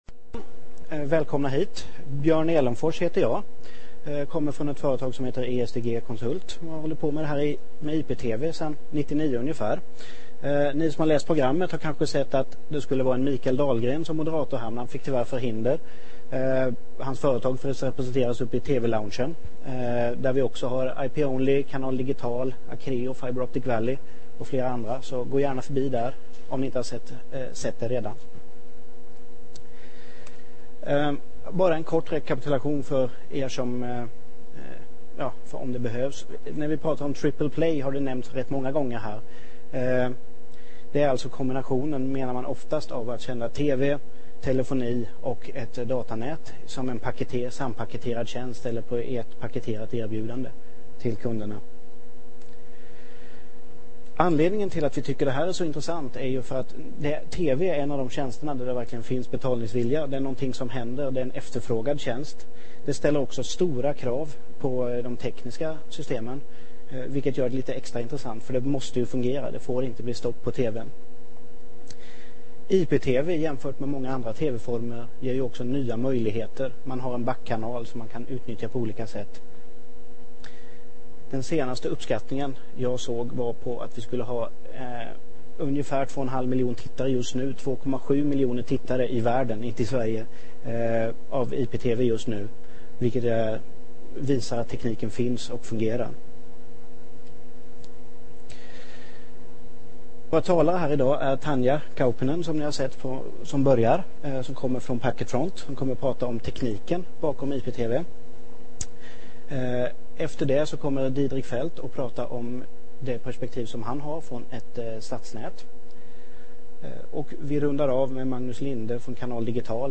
Seminariet ger dig en introduktion till triple-play-tj�nsterna med speciellt fokus p� IPTV och dess p�verkan p� IP-n�t. Genomg�ngen av den bakomliggande tekniken varvas med erfarenheter fr�n ett stadsn�t och en IPTV-operat�r. Seminariet avslutas med en fr�gestund f�r att f�nga �ppna fr�gest�llningar.